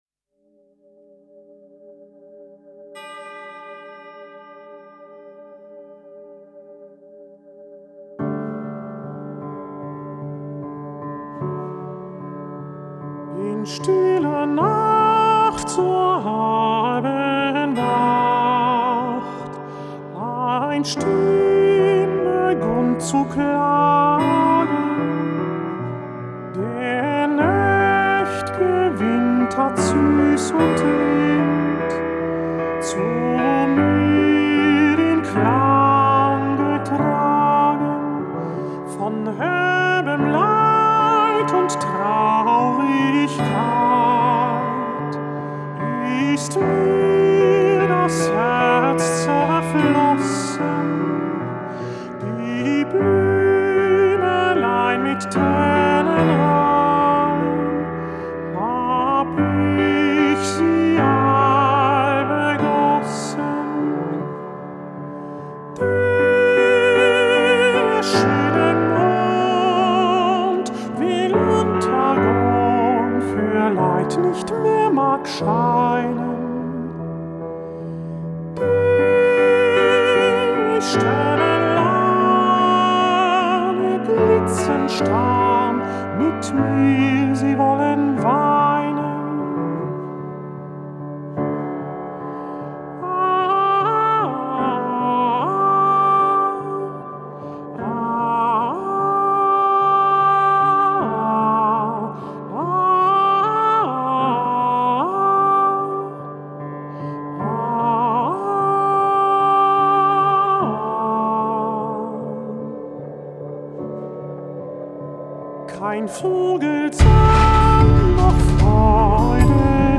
Genre: Worl Music, Israel, Pop, Folk